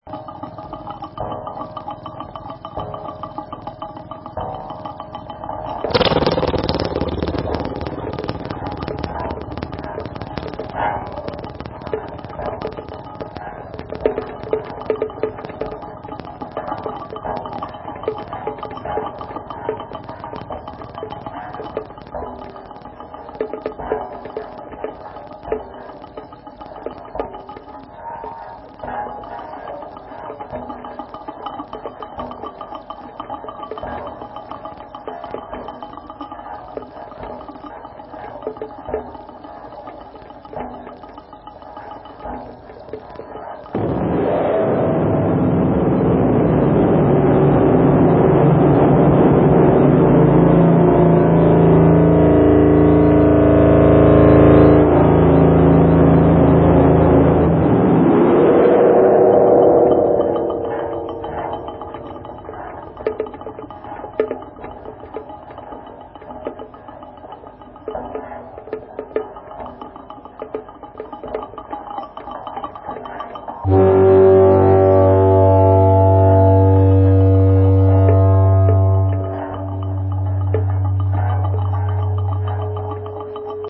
conceptual improvisations,
percussive instruments, rotors, electronics
Audio pictures with machine games.